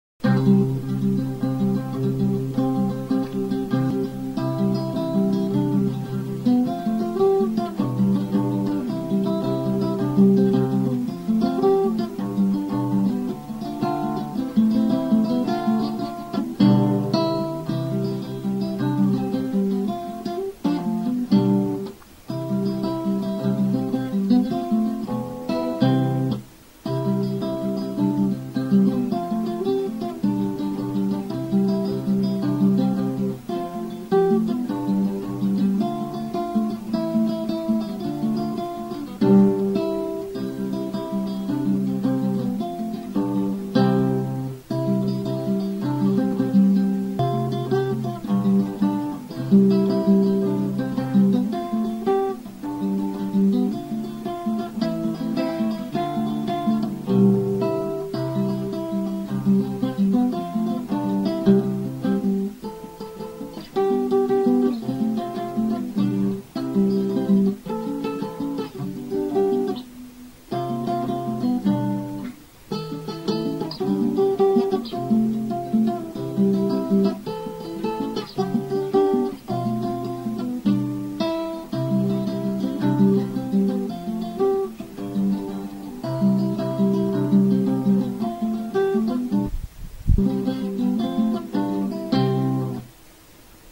Another guitar instrumental composed and played (extremely badly) by Yours Truly.